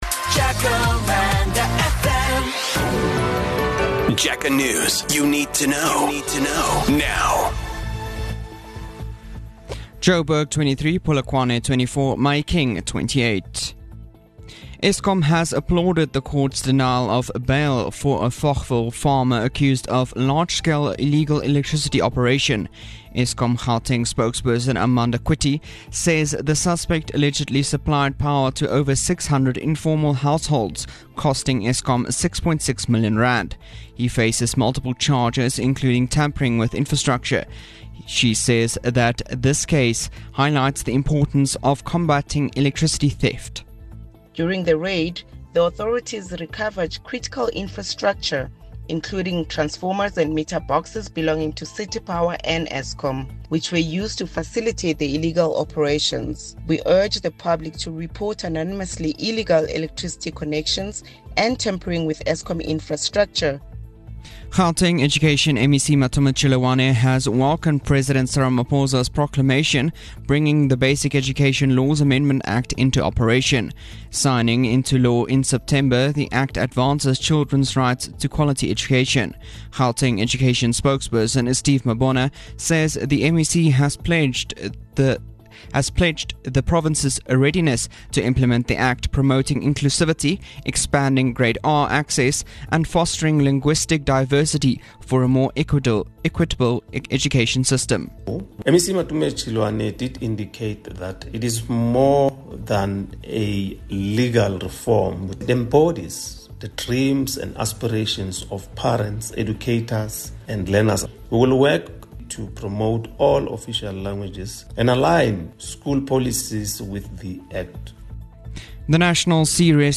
The Jacaranda FM News team is based in Gauteng – but covers local and international news of the day, providing the latest developments online and on-air. News bulletins run from 5am to 7pm weekdays, and from 7am to 6pm on weekends and public holidays.